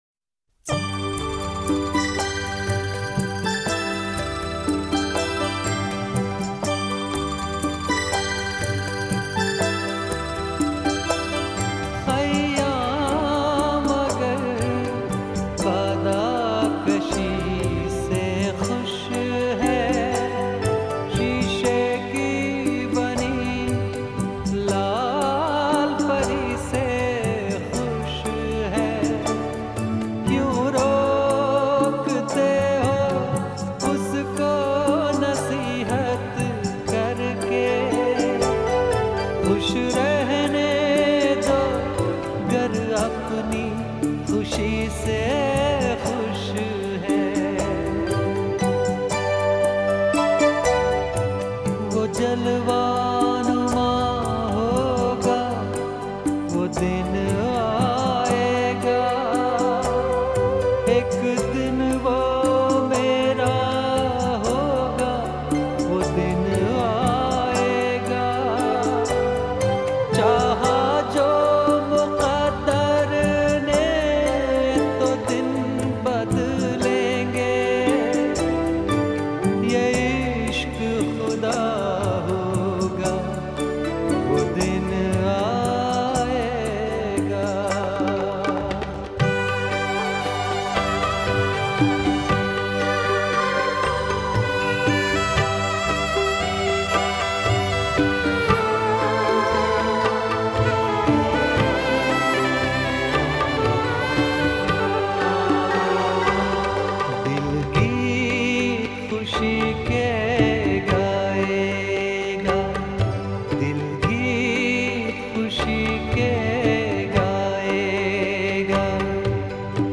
INDIPOP MP3 Songs